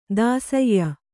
♪ dāsayya